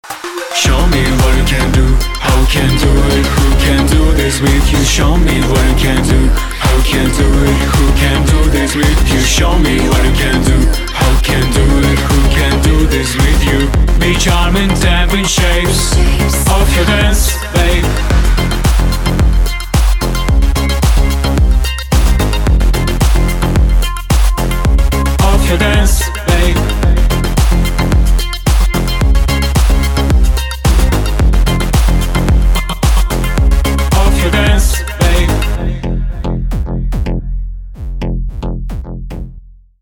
мужской вокал
зажигательные
Synth Pop
Electronic